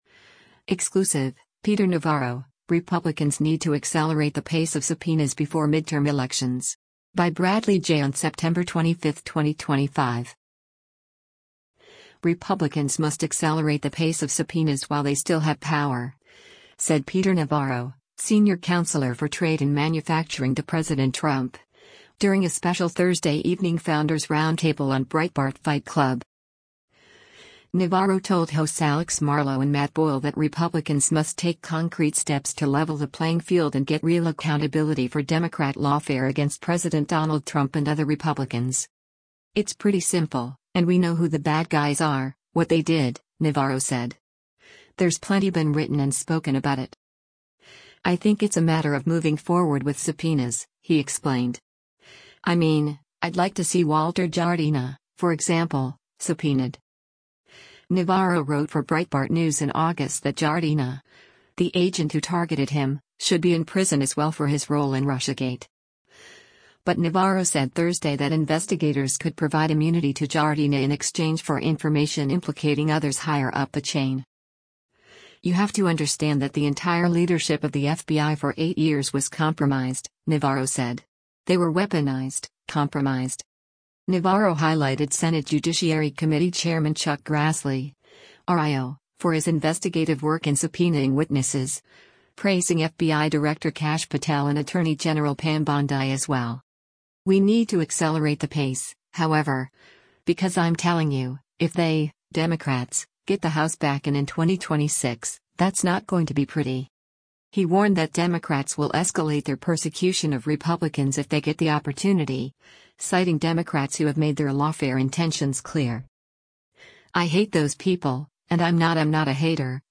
Republicans must accelerate the pace of subpoenas while they still have power, said Peter Navarro, Senior Counselor for Trade and Manufacturing to President Trump, during a special Thursday evening Founders Roundtable on Breitbart Fight Club.